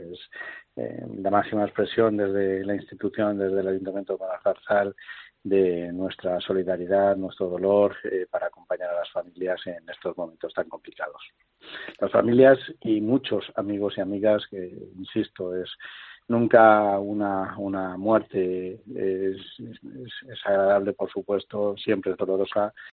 El alcalde de Moralzarzal resume en COPE cómo se encuentran los vecinos del pueblo